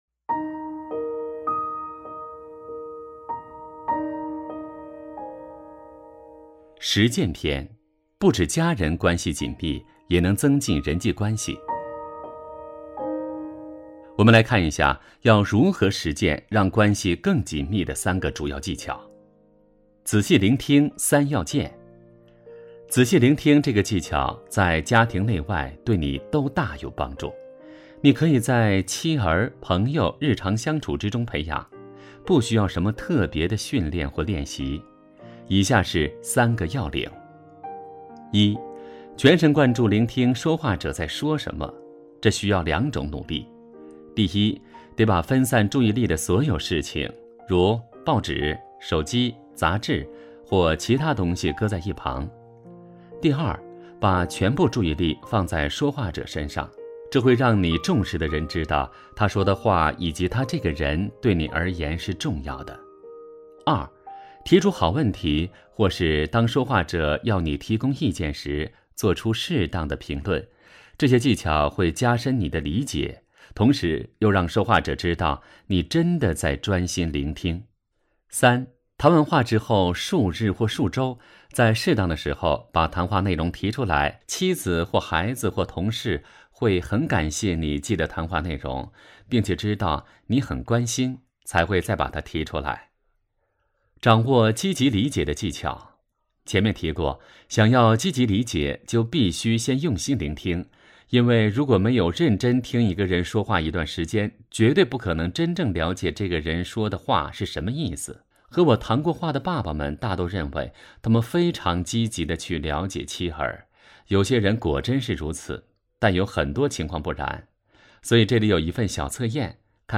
首页 > 有声书 > 婚姻家庭 | 成就好爸爸 | 有声书 > 成就好爸爸：25 实践篇 仔细聆听三要件